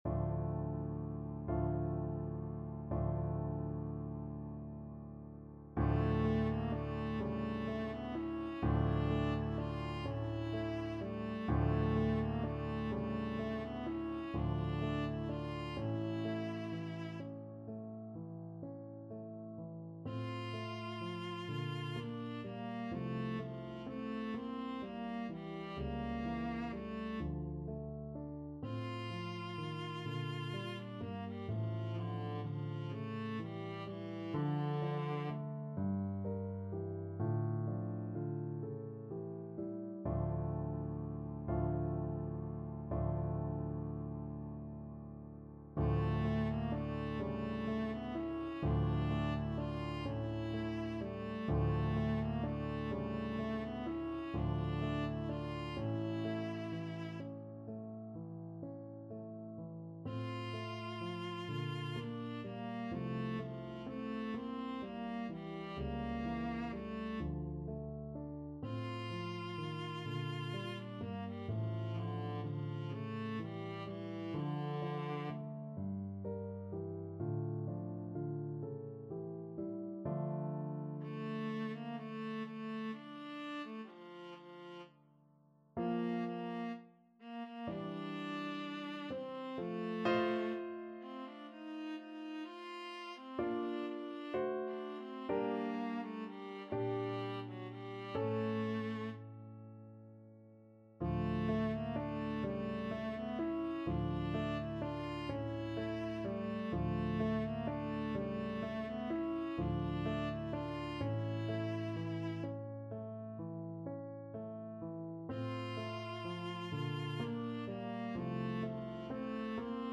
Viola
D minor (Sounding Pitch) (View more D minor Music for Viola )
. = 42 Andante con moto (View more music marked Andante con moto)
6/8 (View more 6/8 Music)
Classical (View more Classical Viola Music)